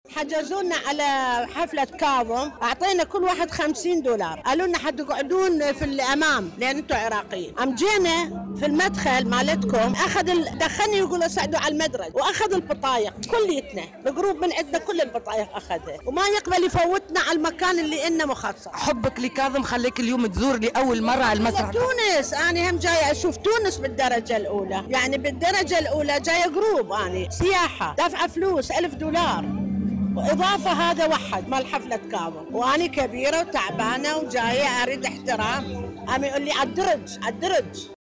سائحة عراقية